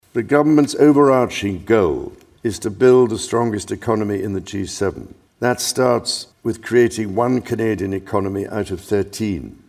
On Tuesday, His Majesty King Charles III delivered the speech from the throne in the Senate chamber, officially launching the first session of the 45th Parliament.
“The true north is indeed, strong and free,” he said, drawing a standing ovation from members of Parliament and Senators.
may27-kingsspeech-06.mp3